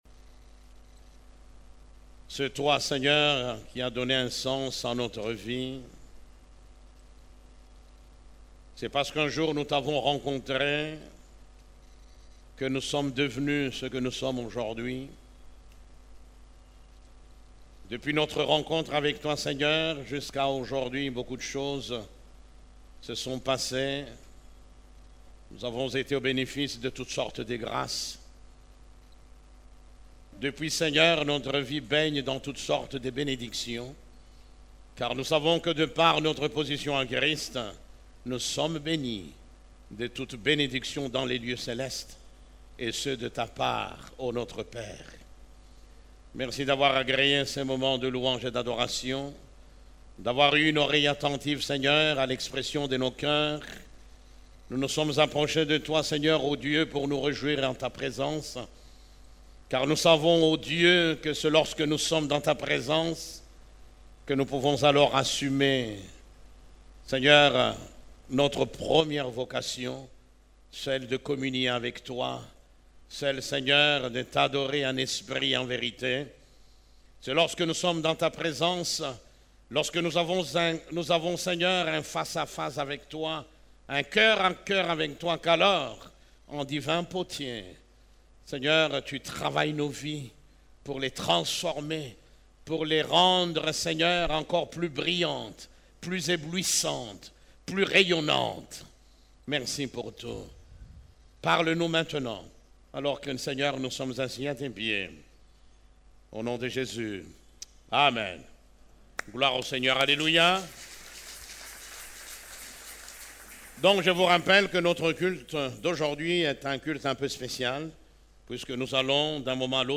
CEF la Borne, Culte du Dimanche, Le mariage est bon